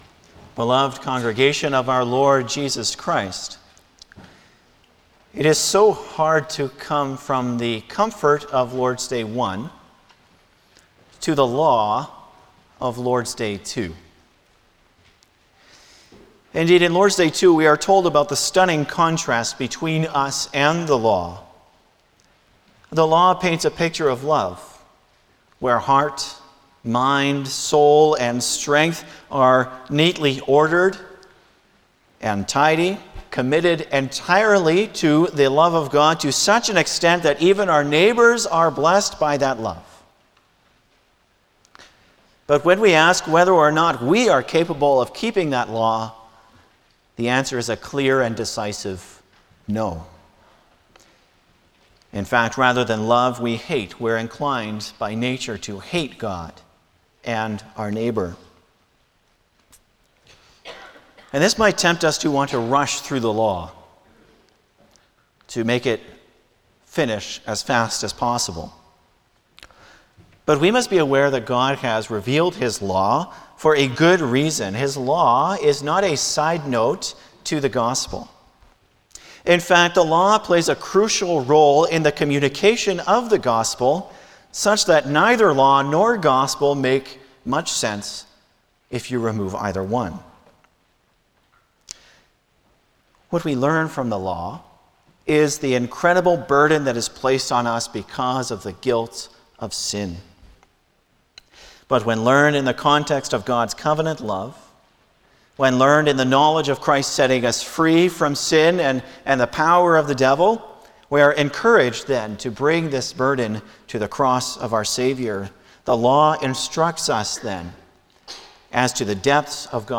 Passage: Lord’s Day 2 Service Type: Sunday afternoon
08-Sermon.mp3